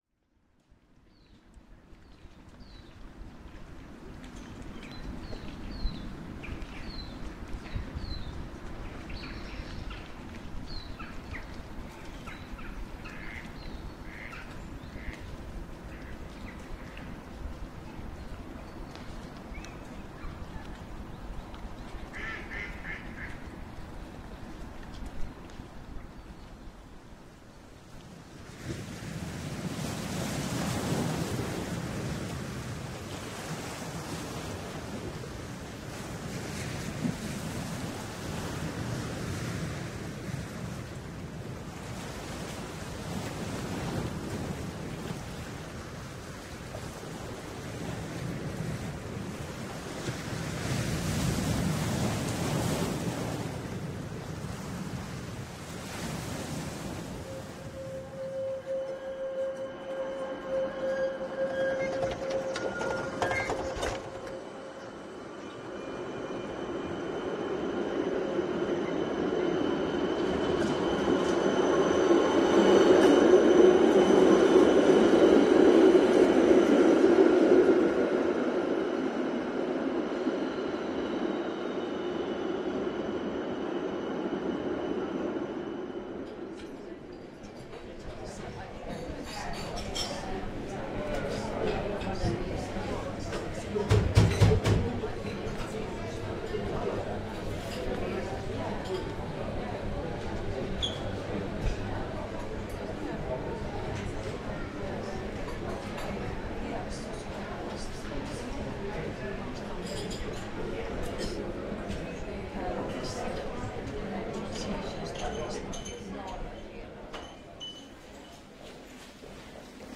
033 - 創造性をときほぐす環境音
033は、環境音を集めたサウンドアプリです。
この星のどこかで確かに鳴った音たちが、あなたを思いがけない場所へと連れていき、創造性を、やわらかくときほぐします。